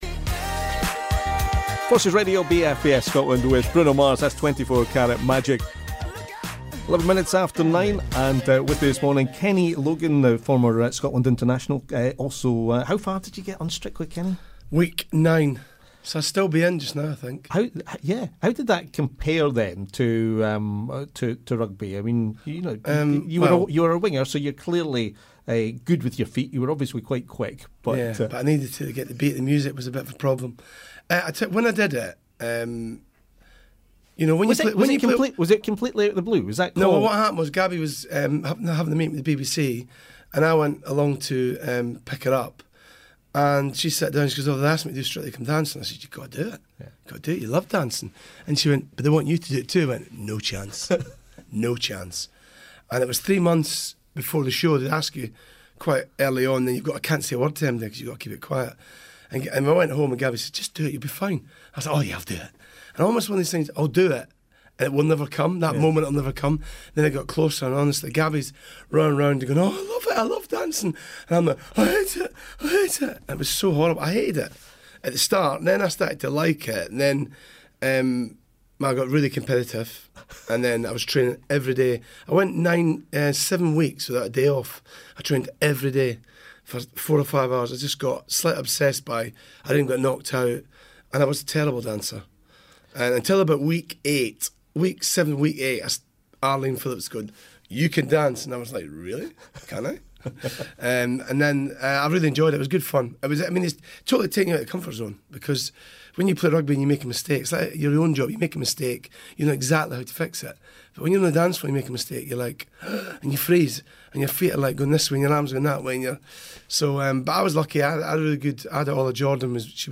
a special St Andrews Day edition of the BFBS Scotland Breakfast Show...